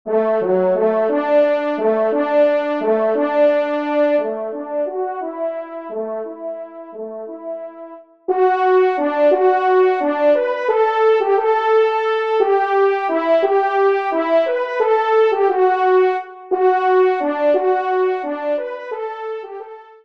Genre :  Divertissement pour Trompes ou Cors
1e Trompe